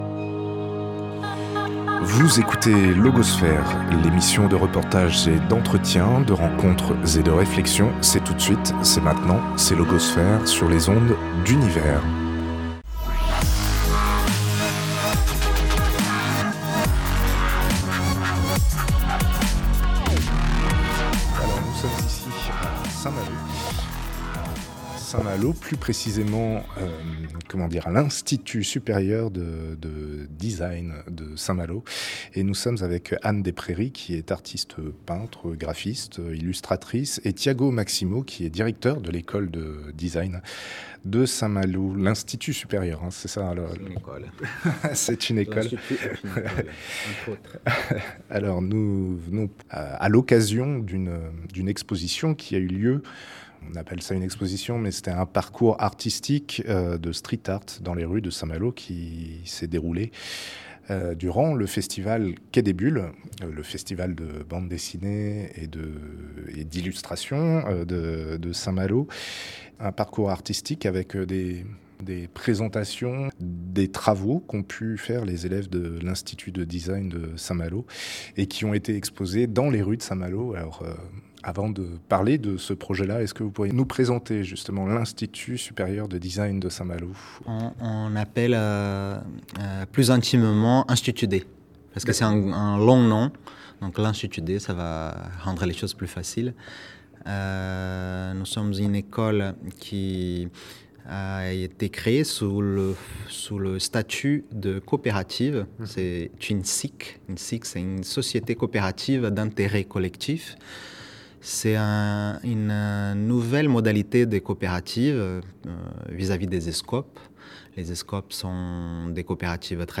Entretien autour de l’école et de l’exposition de street art Rue des bulles qu’elle a mené dans le cadre du festival 2021 de Bande dessinée Quai des bulles.